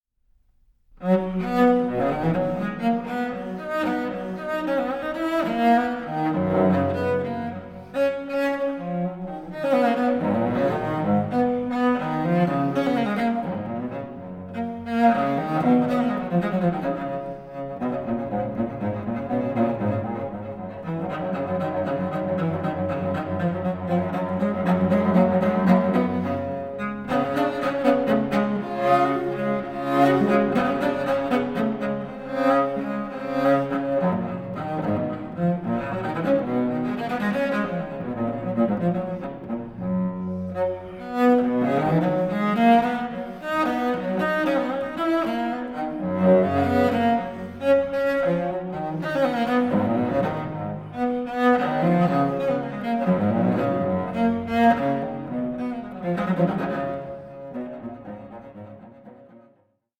baroque cello and cello piccolo